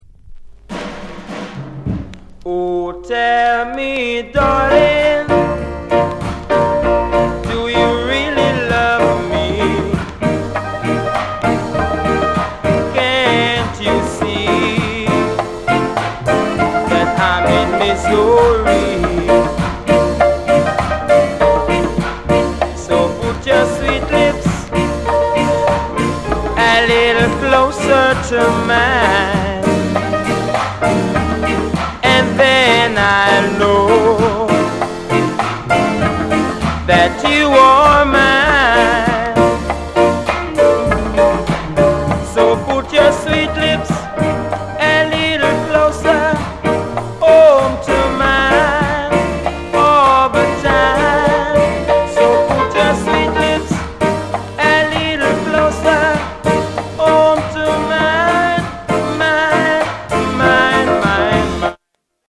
GREAT ROCKSTEADY